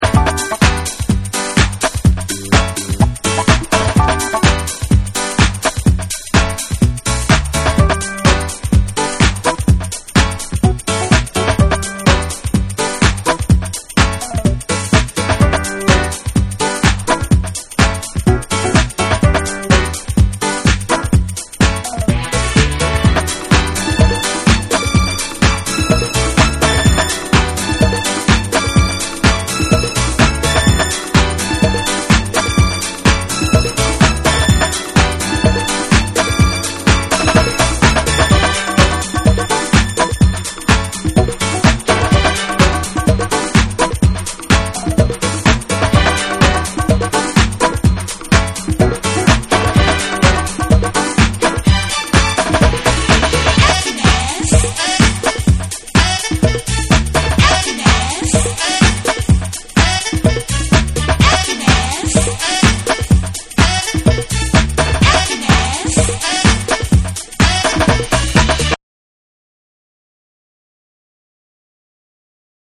気持ち良くハネの効いたトラックにUS産のハウスにも通ずるピアノのフレーズやストリングス・ワーク
TECHNO & HOUSE